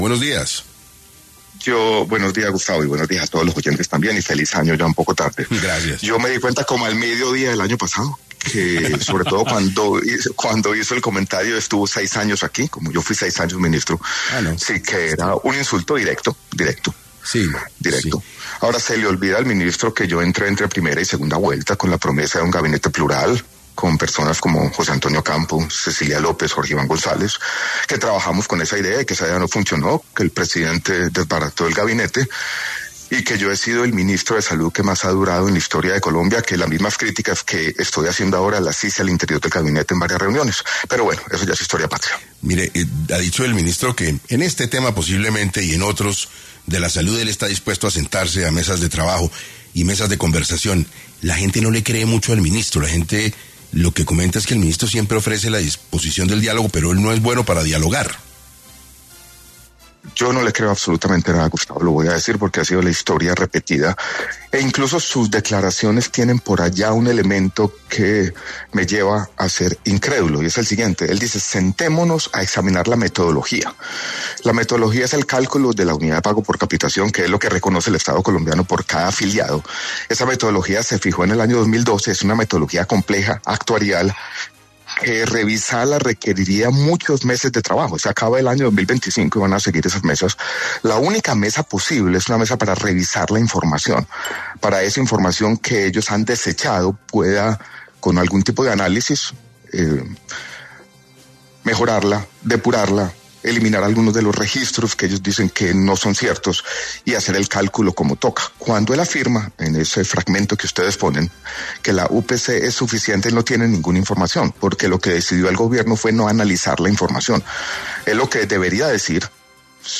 Alejandro Gaviria, exministro de Salud, habló en 6AM sobre qué piensa del señalamiento de “tráfuga” del ministro Guillermo Jaramillo contra un exintegrante del gobierno que propuso una unidad de pago por capitación del 20%